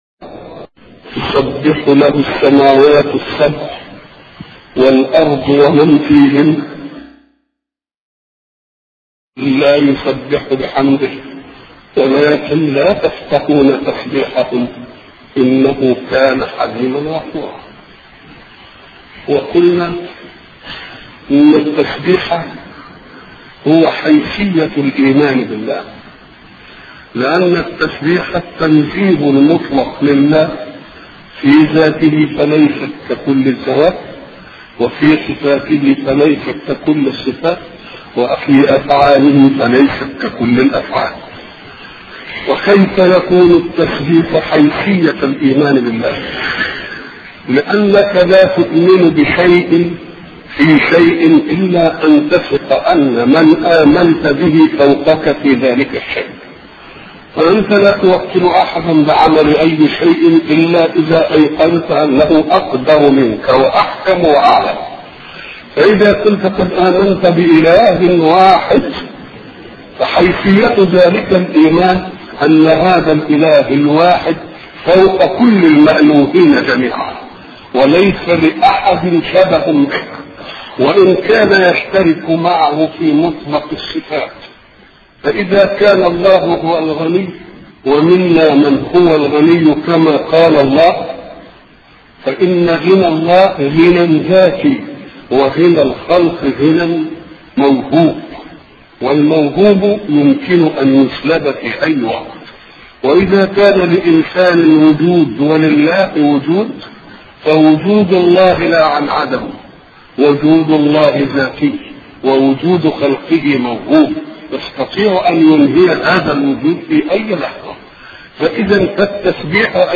أرشيف الإسلام - ~ أرشيف صوتي لدروس وخطب ومحاضرات الشيخ محمد متولي الشعراوي